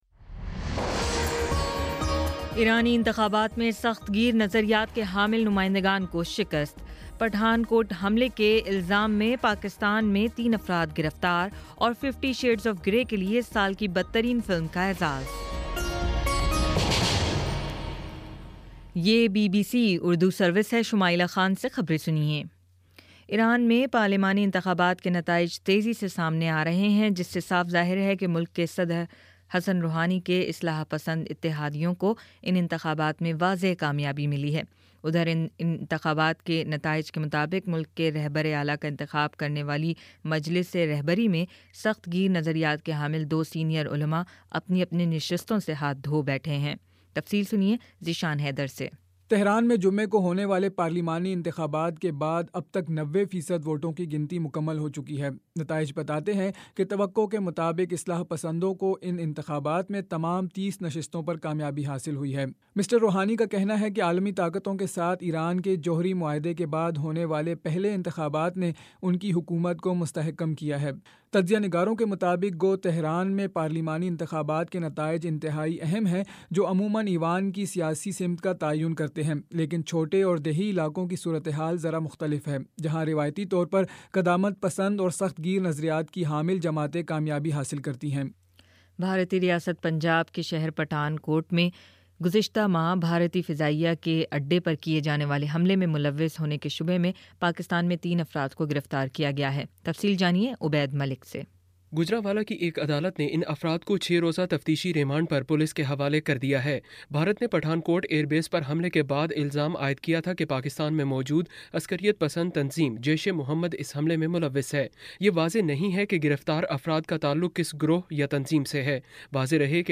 فروری 28: شام سات بجے کا نیوز بُلیٹن